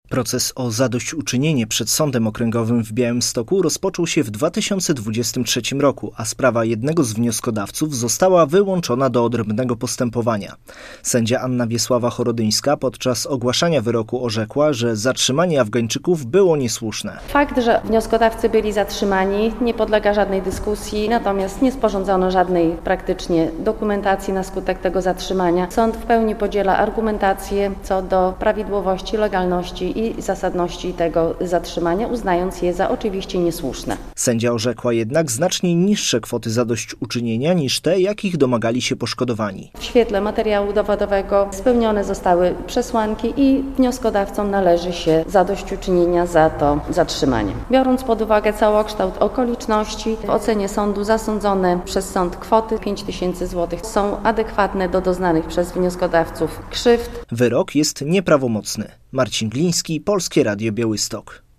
Wiadomości - Sąd przyznał zadośćuczynienie Afgańczykom za niesłuszne zatrzymanie na granicy